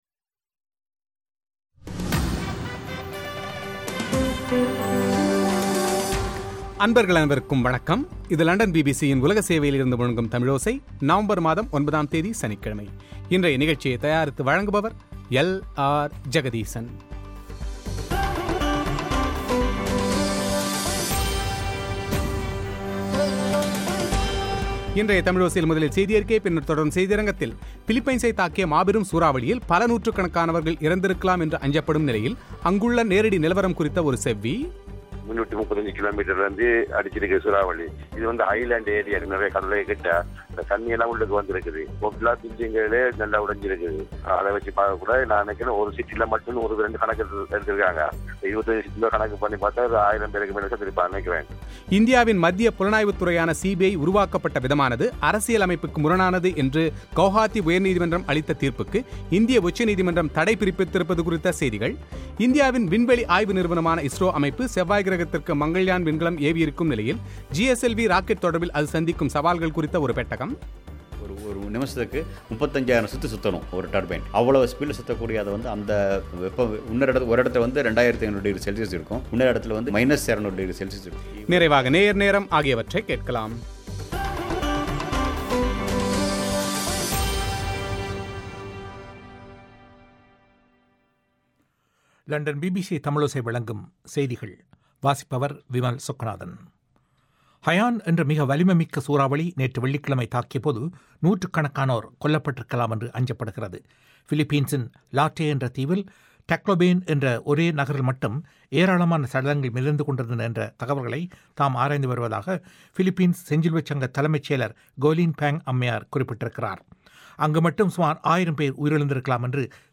பிலிப்பன்ஸை தாக்கிய மாபெரும் சூறாவளியில் பல நூற்றுக்கணக்கானவர்கள் இறந்திருக்கலாம் என்று அஞ்சப்படும் நிலையில் அங்குள்ள நிலவரம் குறித்து அங்கு வாழும் ஒருவரின் செவ்வி;